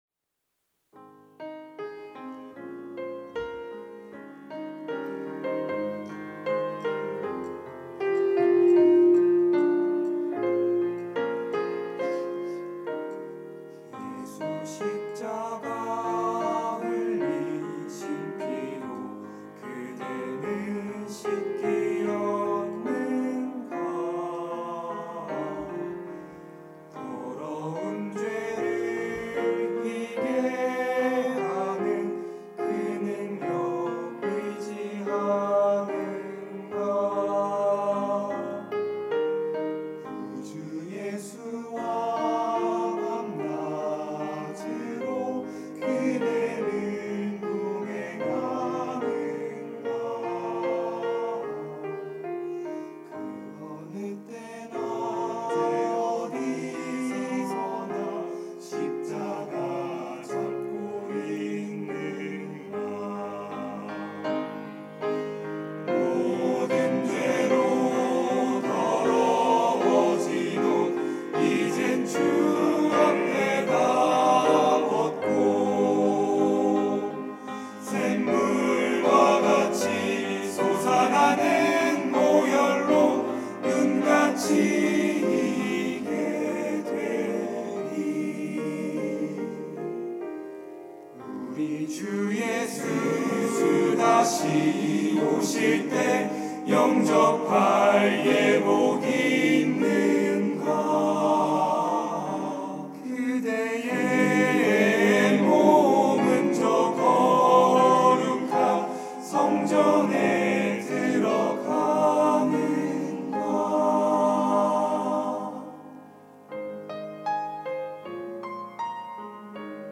특송과 특주 - 그대는 씻기어 있는가